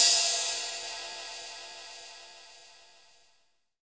Index of /90_sSampleCDs/Roland L-CDX-01/CYM_FX Cymbals 1/CYM_Cymbal FX
CYM BRCR 104.wav